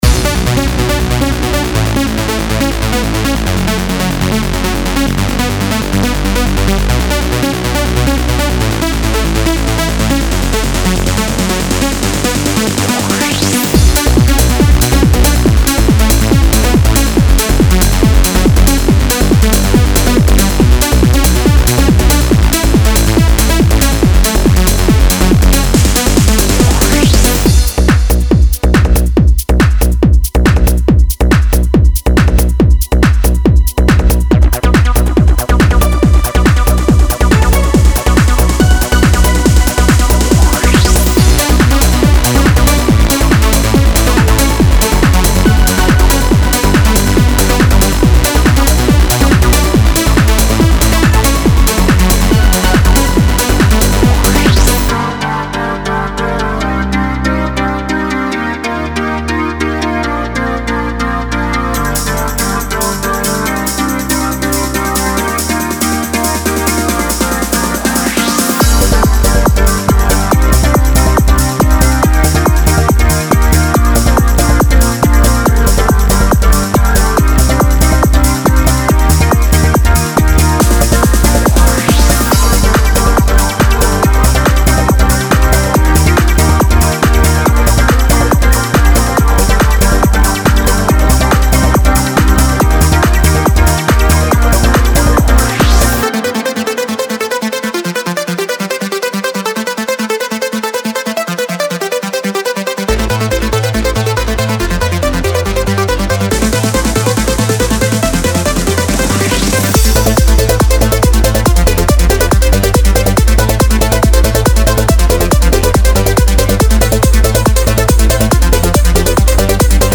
Type: Serum
• Recorded in 135-143 Bpm & Key labelled